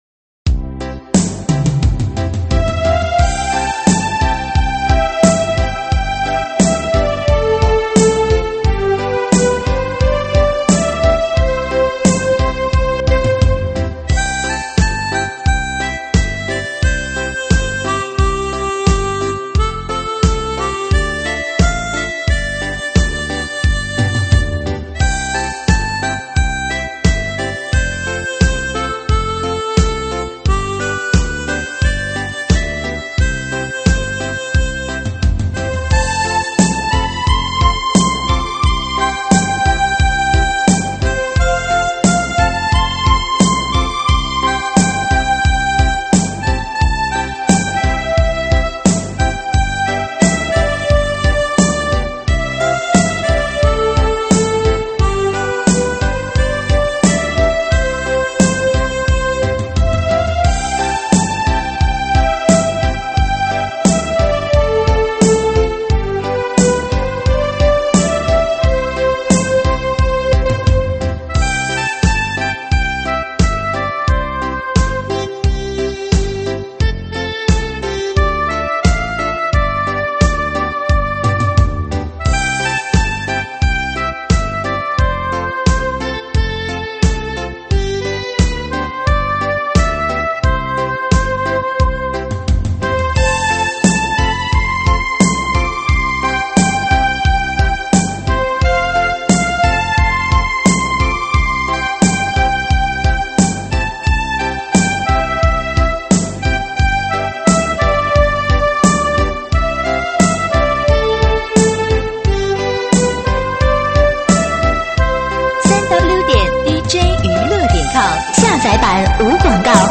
慢摇舞曲